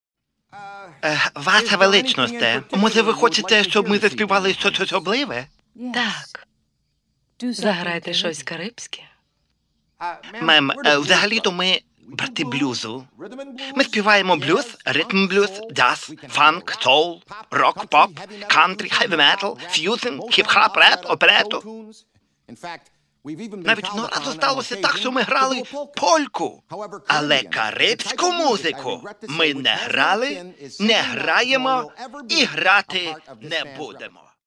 Mp3Репертуар (скіт by Юрій Горбунов)